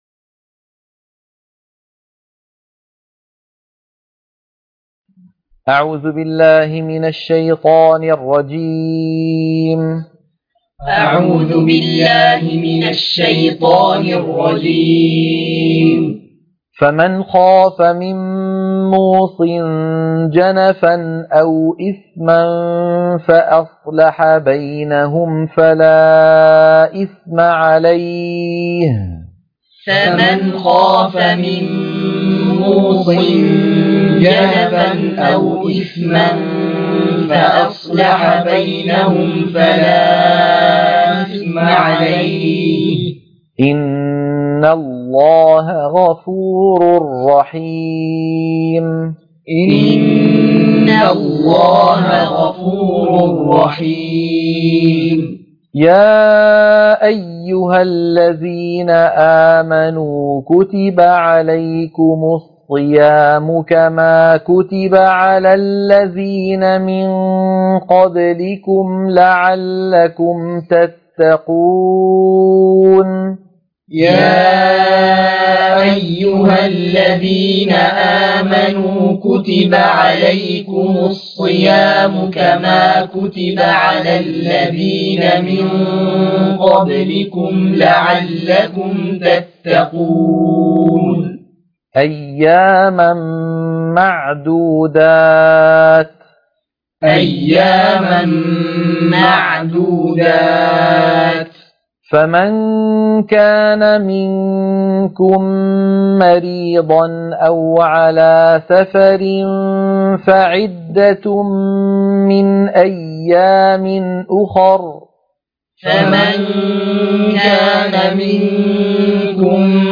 عنوان المادة تلقين سورة البقرة - الصفحة 28 _ التلاوة المنهجية